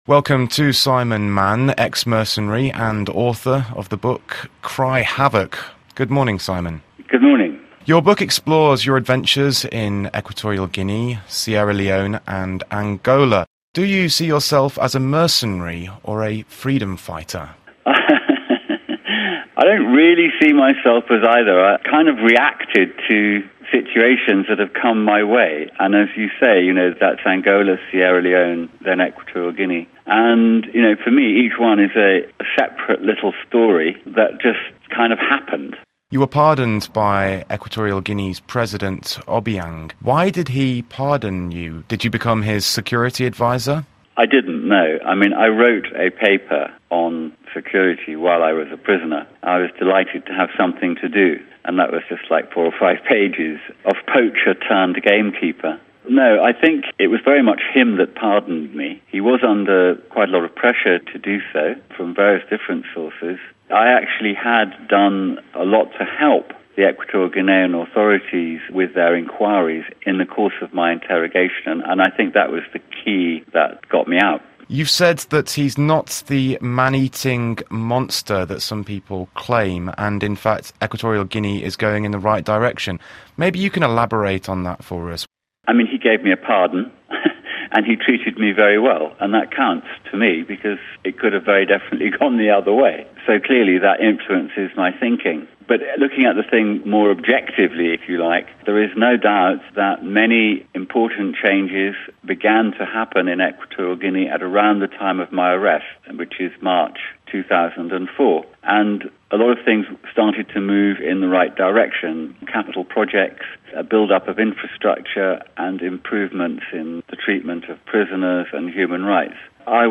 Interview: Simon Mann, former mercenary & author of Cry Havoc